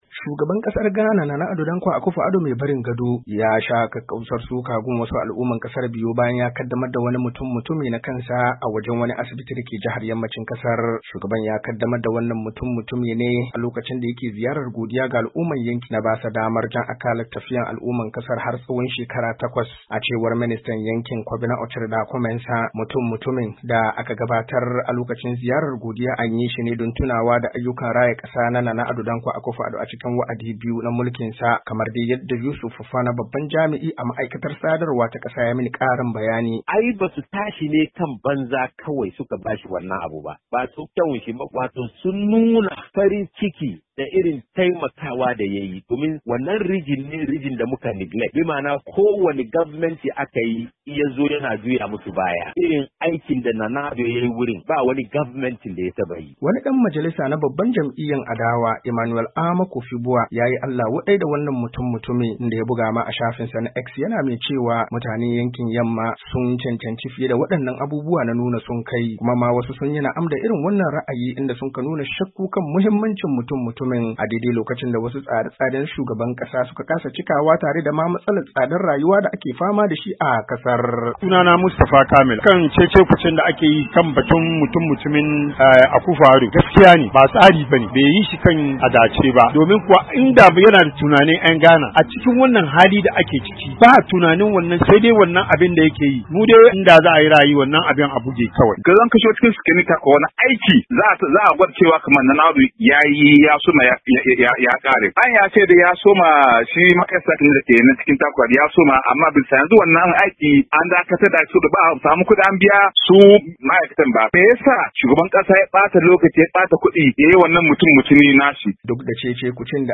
RAHOTON CECE KUCE AKAN GINA MUTUM MUTUMIN SHUGABAN GHANA .mp3